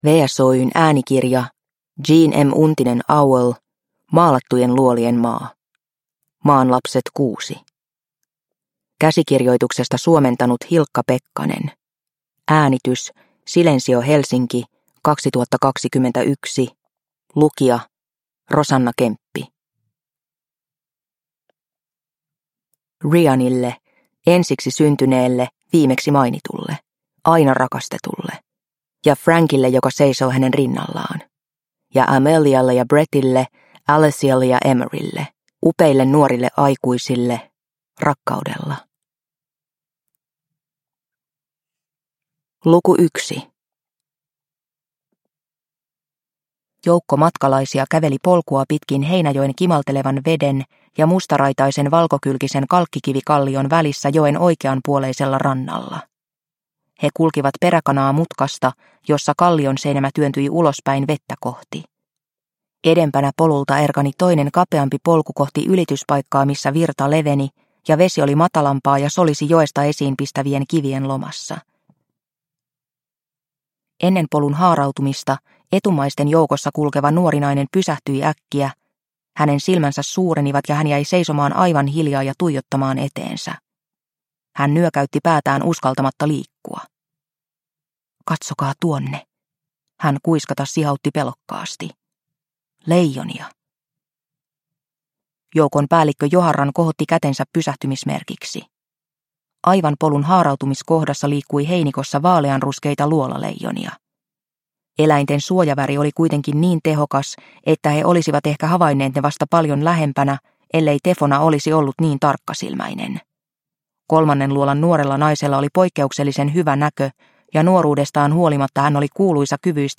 Maalattujen luolien maa – Ljudbok – Laddas ner